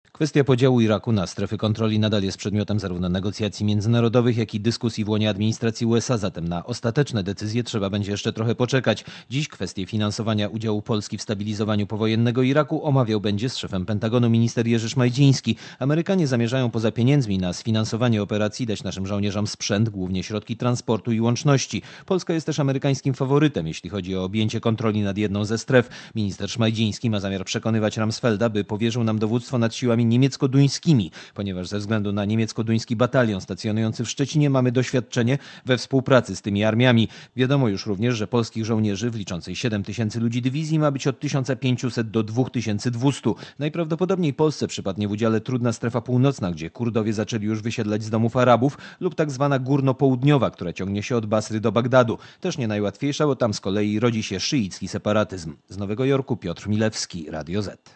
Posłuchaj relacji korespondenta Radia Zet (465 kb)